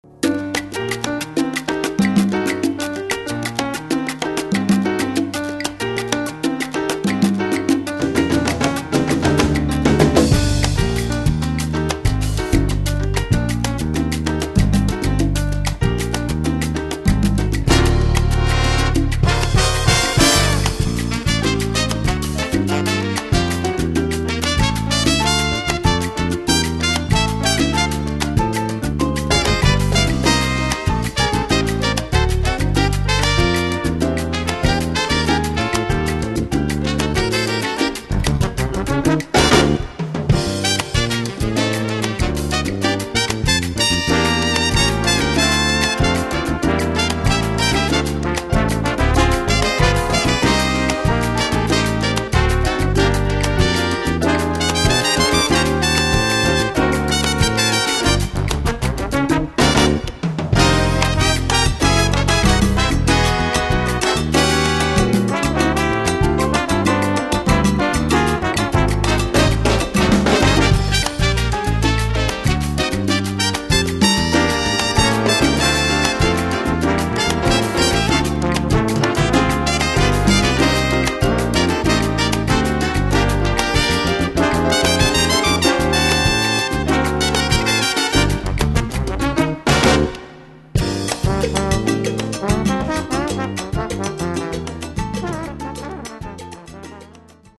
Category: combo
Style: cubop
Solos: trumpet 2, trombone 2